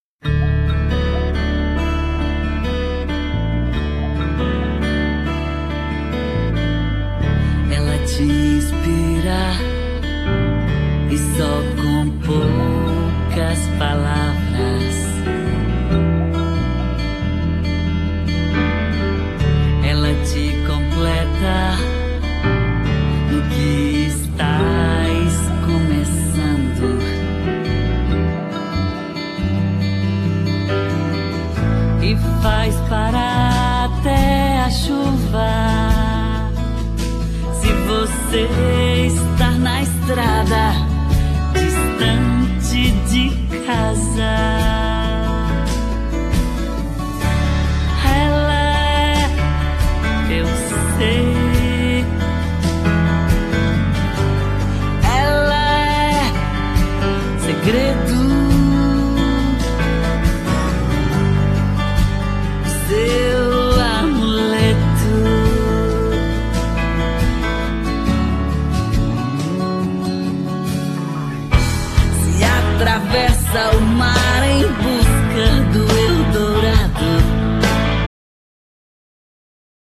Genere : Latino / Pop